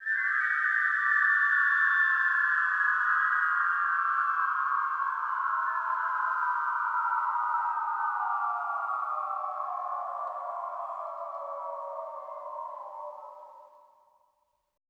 WH CL FALL-L.wav